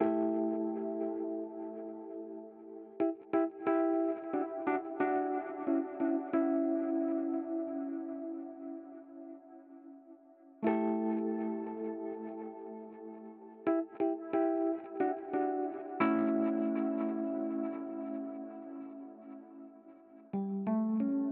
描述：hodes layered with pluck 我很高兴听到你做的项目，请留言 :)
标签： 90 bpm Chill Out Loops Rhodes Piano Loops 3.59 MB wav Key : A FL Studio
声道立体声